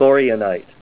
Say THORIANITE Help on Synonym: Synonym: ICSD 67413   PDF 42-1462